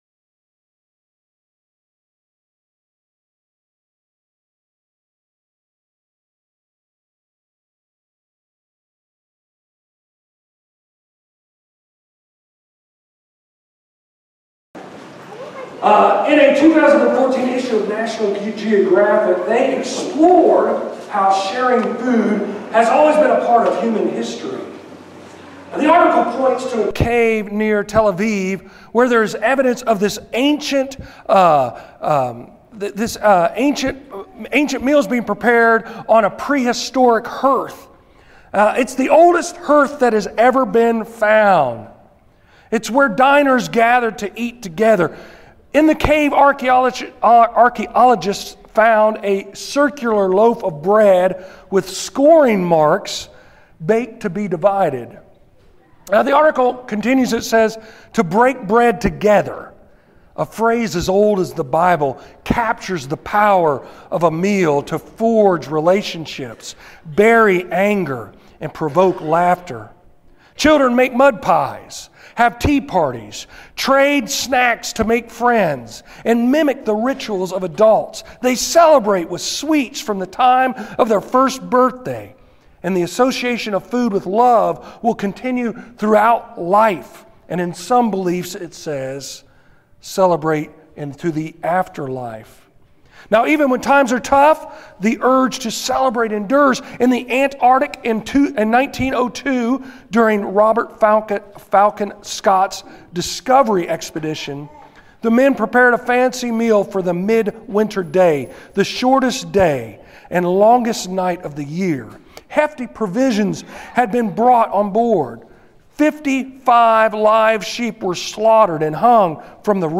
30:03 Sermons in this series Sent!